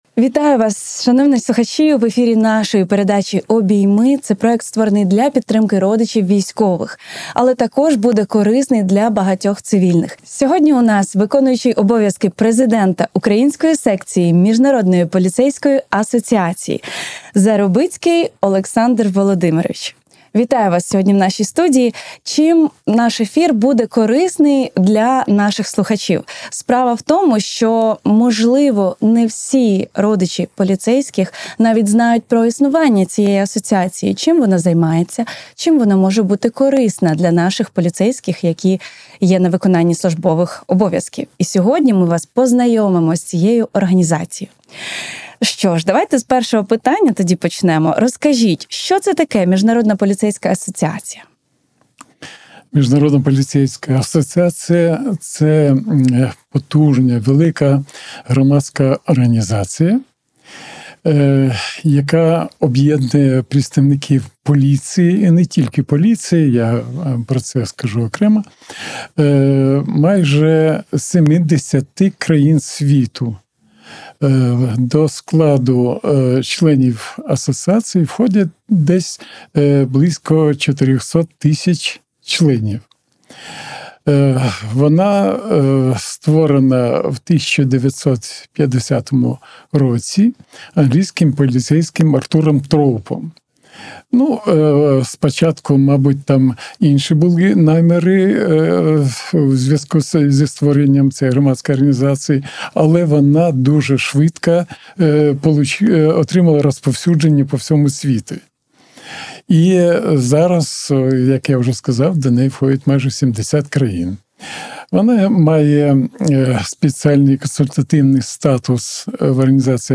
Це розмова, яку варто почути кожному, хто служить у поліції. Бо тут — про підтримку, проєкти, об’єднання, які не мають кордонів.